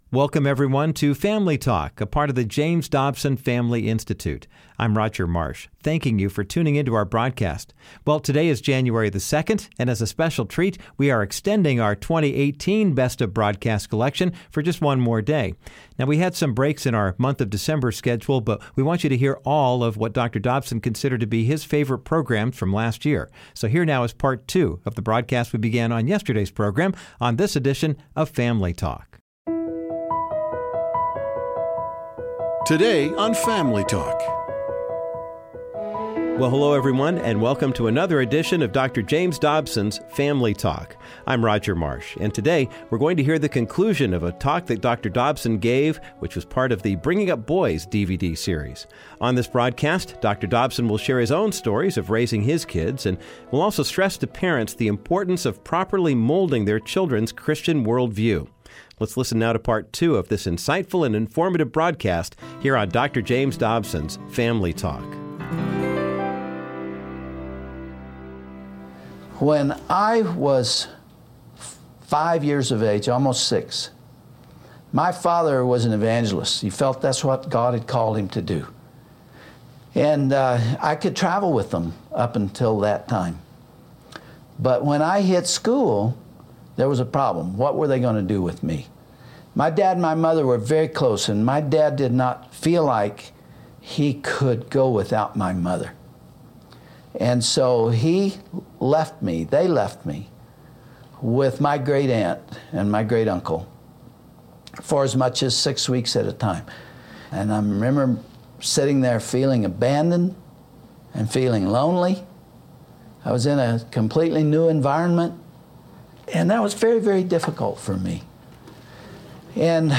On this edition of Family Talk youll hear the remainder of Dr. Dobsons speech from his popular Bringing Up Boys DVD. He examined the challenges Christian parents endure by sharing stories of raising his own children. Dr. Dobson also emphasized to parents the value of forming a childs Christian worldview at a young age.